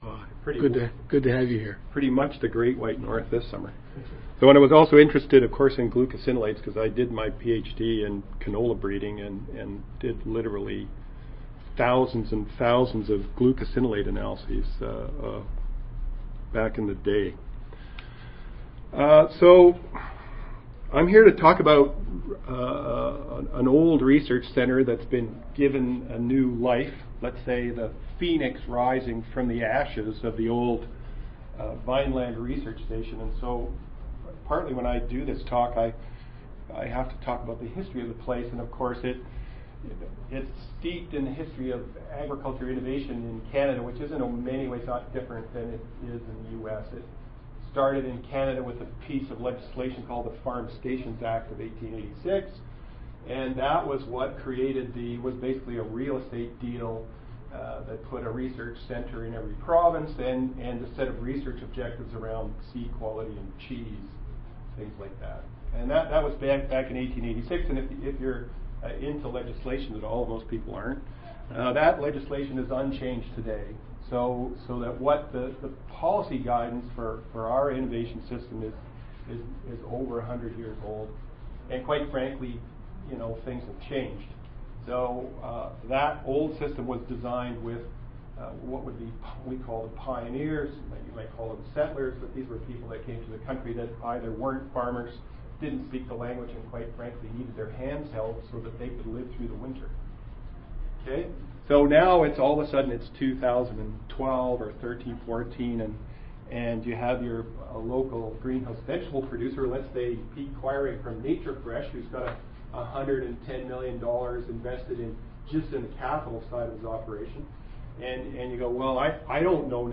2014 ASHS Annual Conference: Workshop: Spotlight on Centers for Innovation in Research and Development